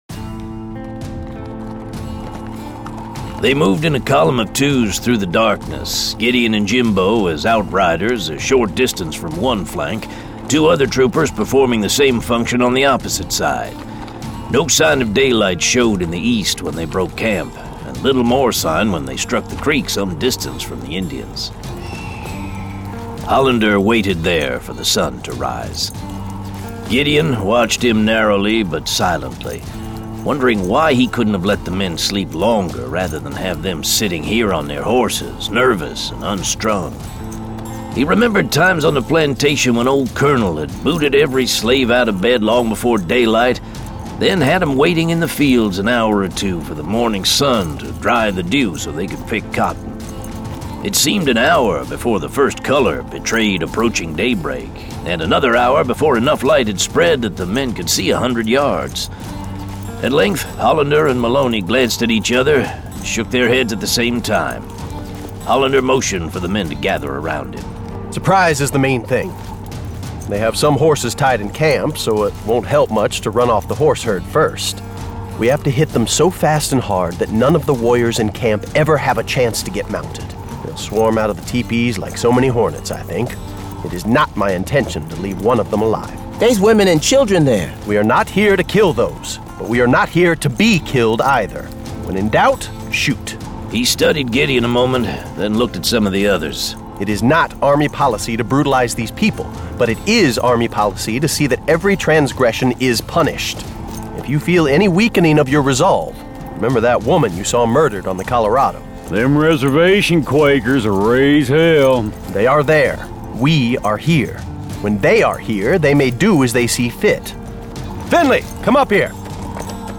The Wolf and the Buffalo 2 of 2 [Dramatized Adaptation]